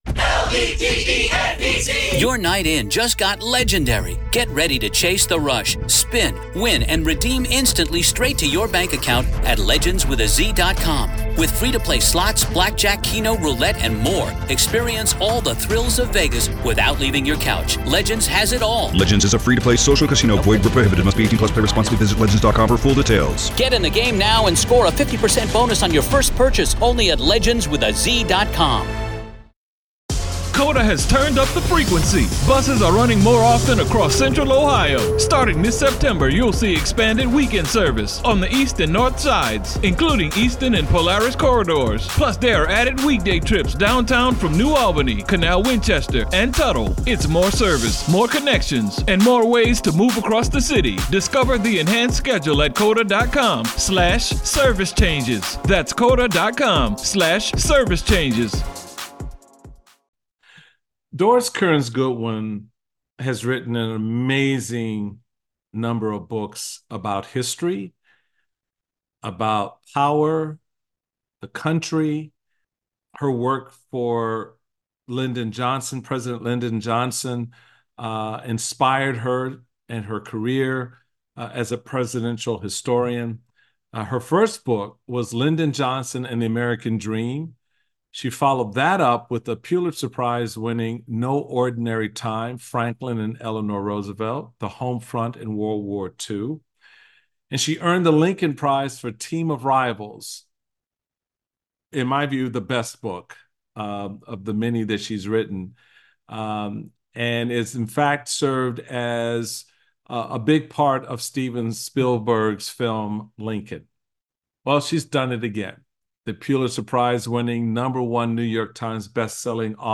Michael Steele speaks with historian, Pulitzer Prize winner and best-selling author, Doris Kearns Goodwin.